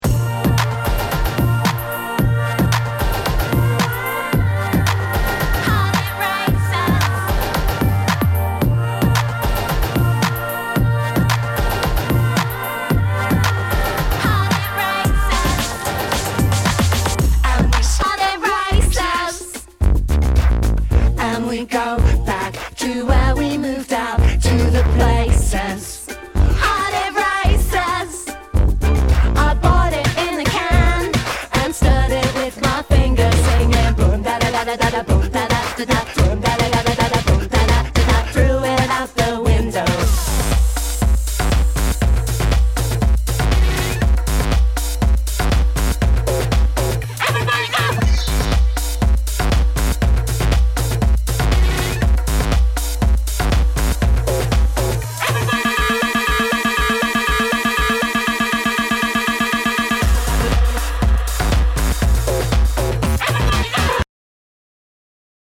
HOUSE/TECHNO/ELECTRO
ナイス！エレクトロ！